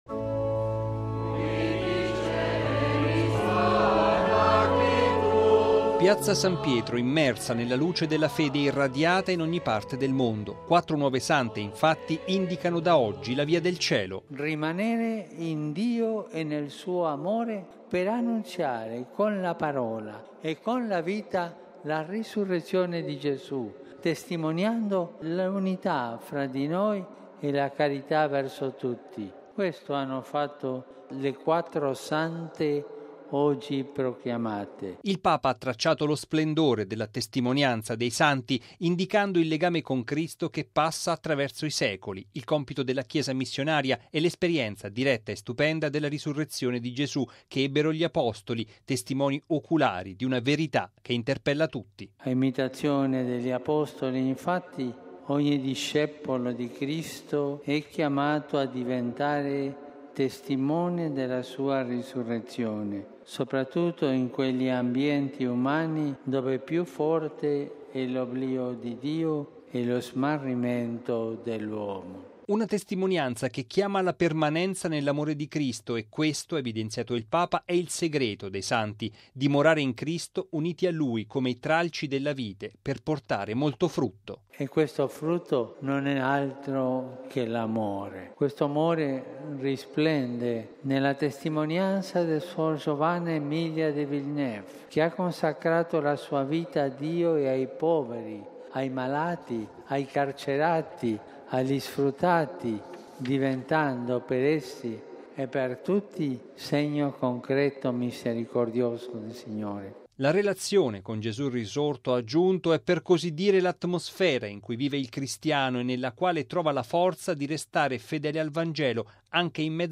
Così, in sintesi, il Papa, nella Solennità dell’Ascensione, durante la Messa per la proclamazione di quattro nuove sante: la religiosa francese Giovanna Emilia De Villeneuve, le due suore palestinesi Maria Alfonsina Danil Ghattas e Maria di Gesù Crocifisso e la religiosa napoletana Maria Cristina Brando.